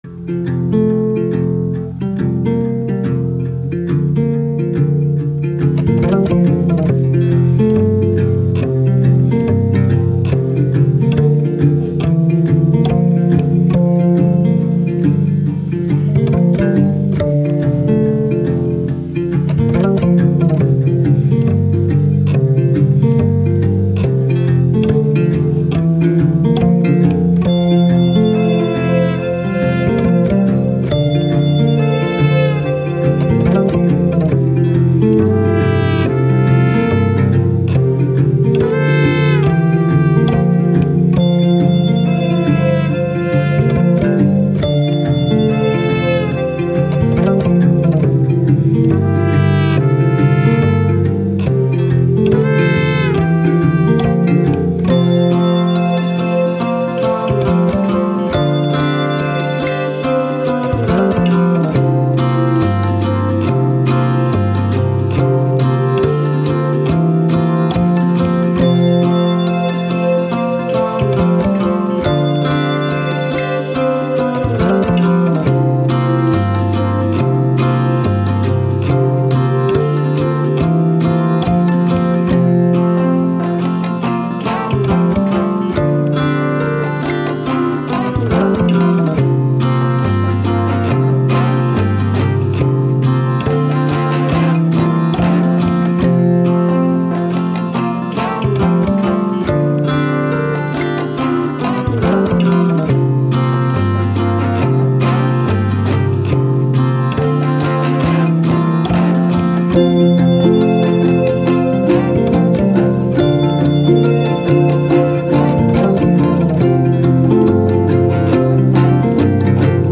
Ambient, Soundtrack, Downtempo, Positive, Happy